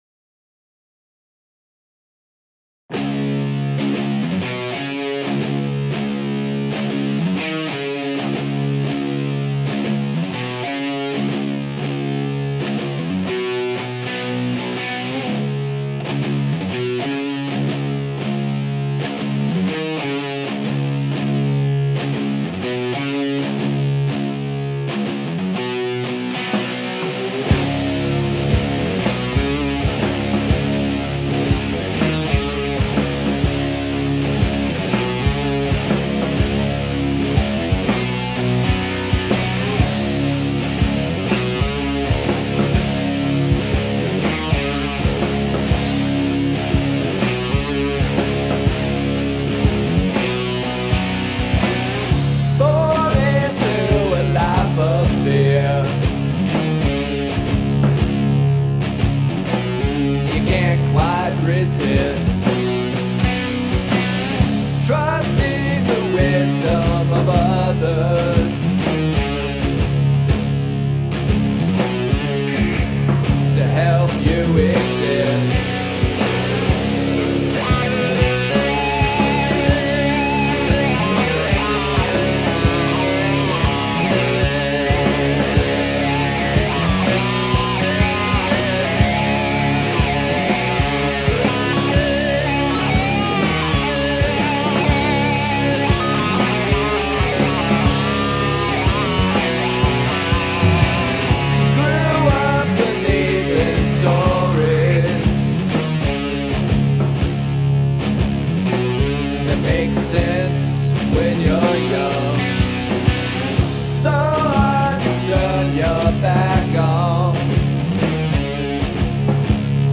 Rock & Roll
Rock/Hard-rock